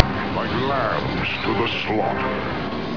Sound Bytes of the Apocalypse! From X-Men: The Animated Series.